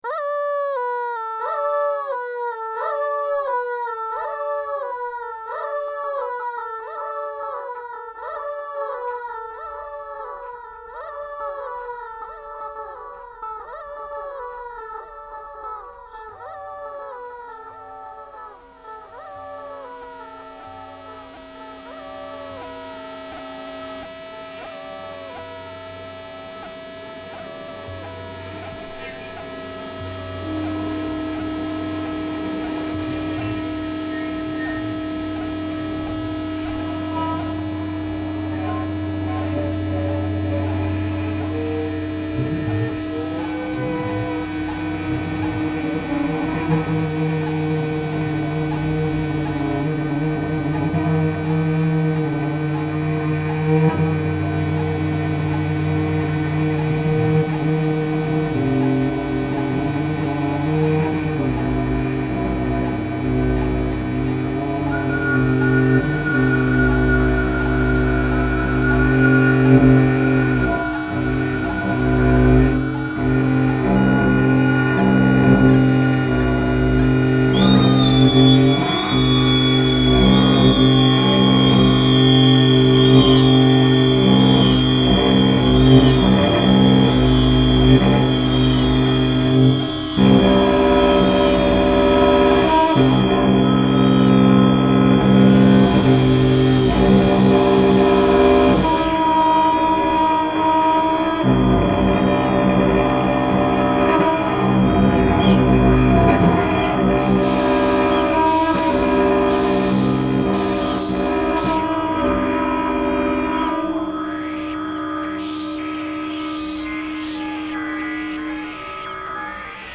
La Garenne-Colombes le 11 février 2012
improvisatrice violoncelliste française